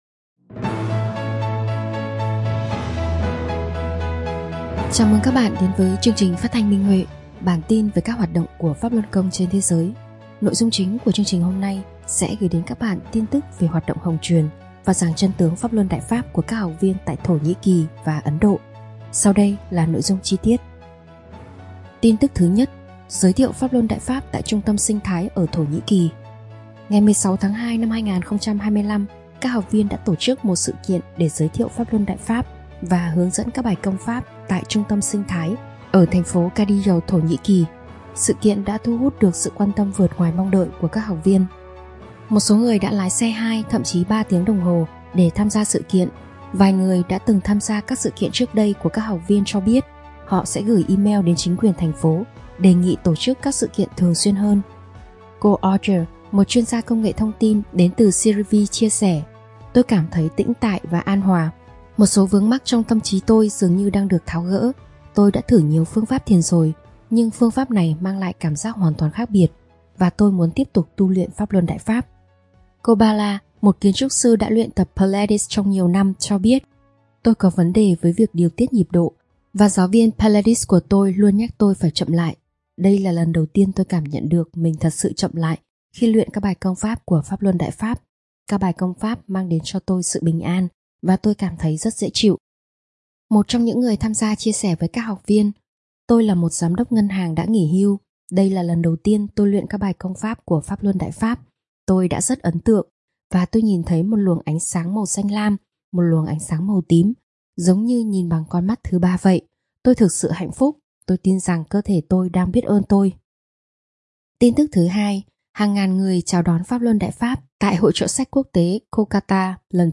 Chương trình phát thanh số 291: Tin tức Pháp Luân Đại Pháp trên thế giới – Ngày 3/3/2025